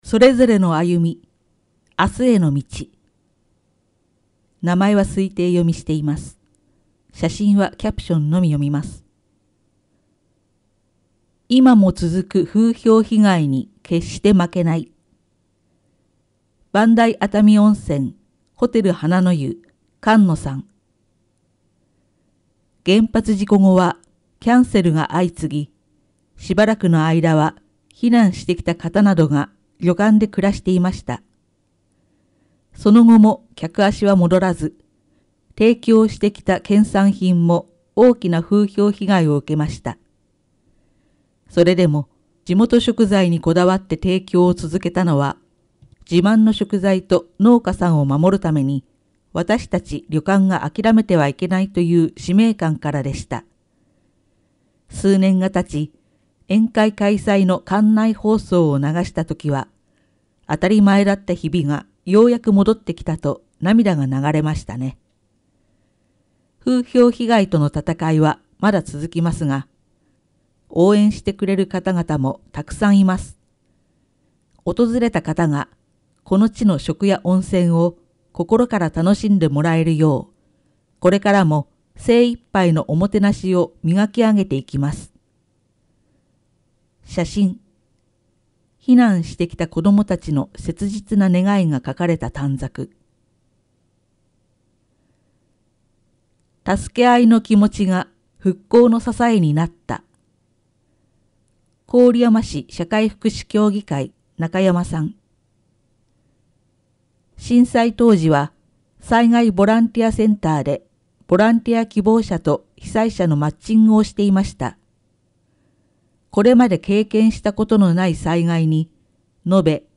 「声の広報」は、「広報こおりやま」の一部記事を「視覚障がい者支援ボランティアグループ　くるみ会」の皆さんが読み上げています。